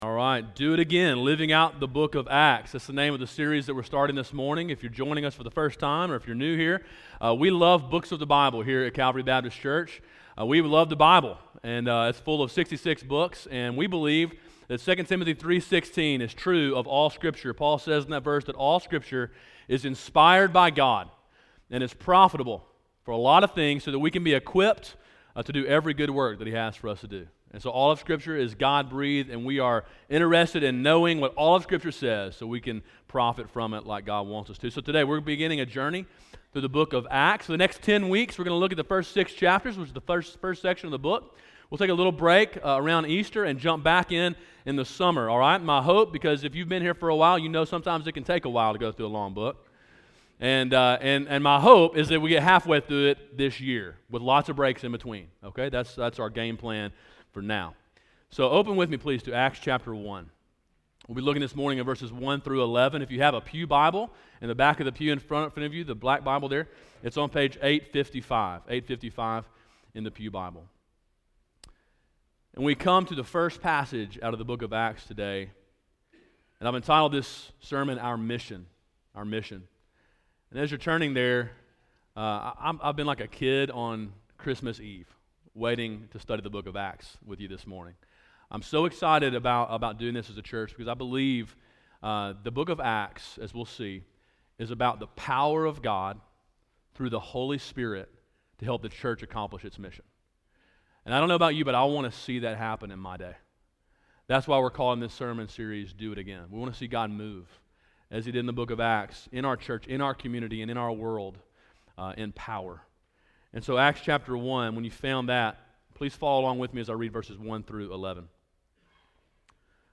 Sermon: “Our Mission” (Acts 1:1-11) – Calvary Baptist Church